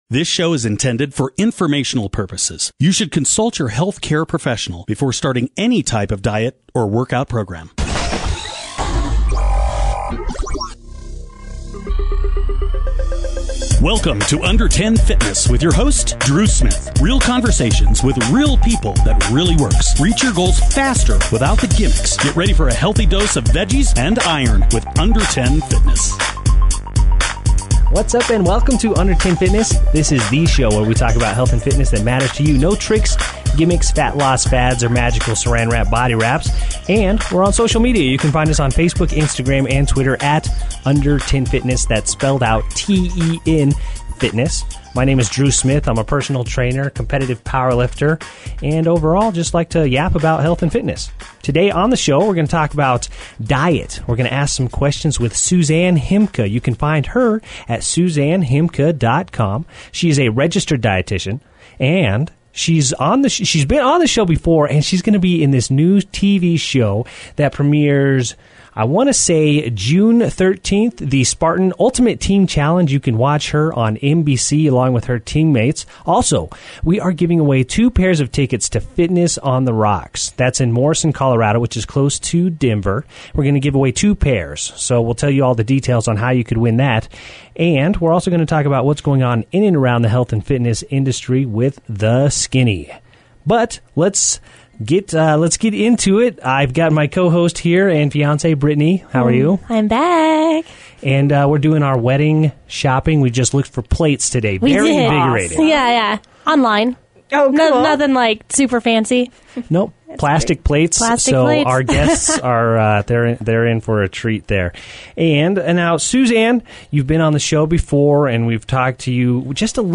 60 Minutes with Dietitian